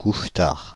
Ääntäminen
Synonyymit oiseau de nuit lève-tard noctambule ambianceuse ambianceur Ääntäminen Paris Tuntematon aksentti: IPA: /kuʃ.taʁ/ Haettu sana löytyi näillä lähdekielillä: ranska Käännös 1. éjjeli bagoly Suku: m .